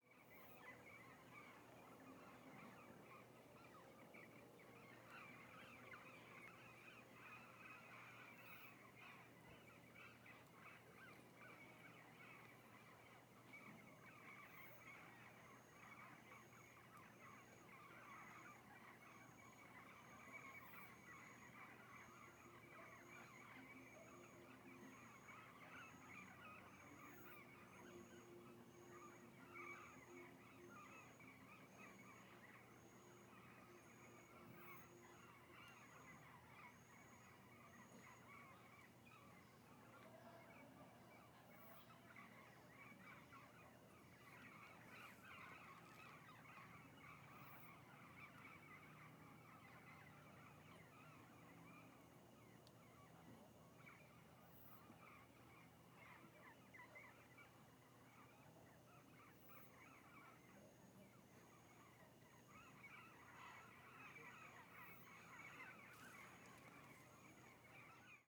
CSC-05-023-GV - Ambiencia de fim de tarde no Parque Nacional da Chapada dos Veadeiros com Maritacas ao fundo .wav